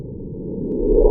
end-slow-down.ogg